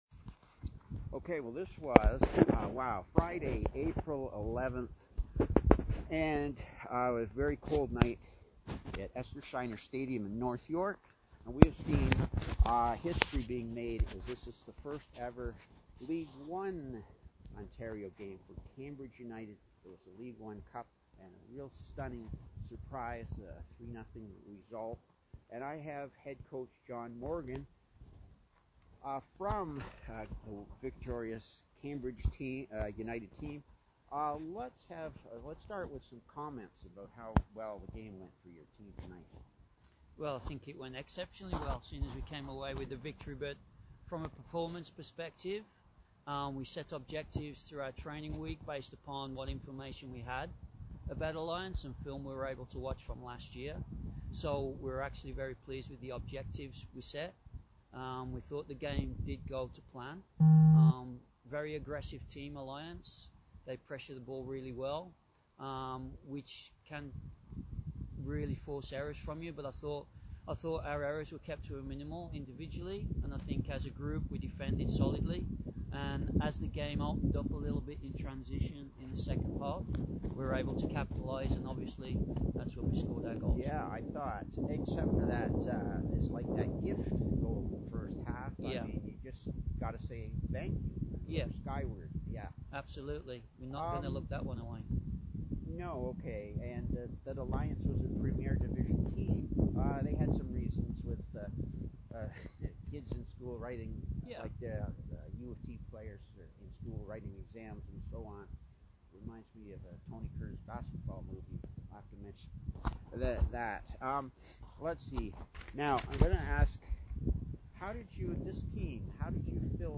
Post game interview